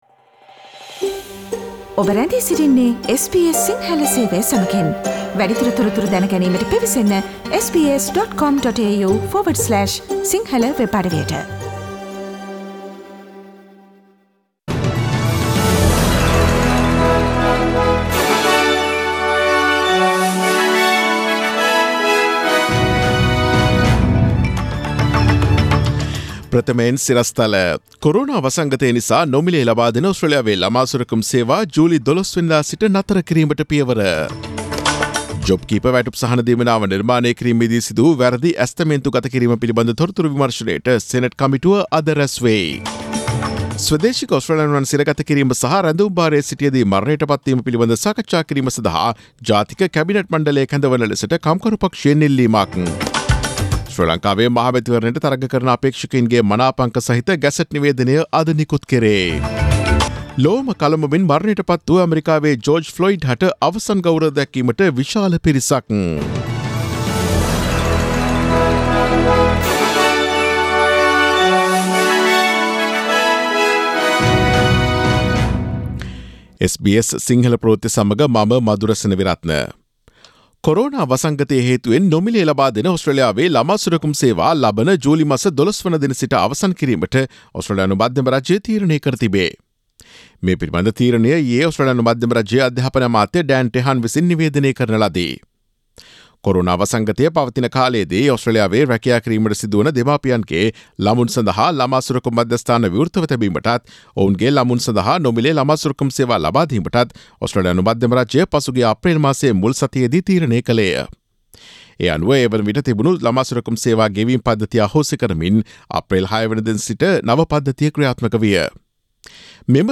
Daily News bulletin of SBS Sinhala Service: Tuesday 09 June 2020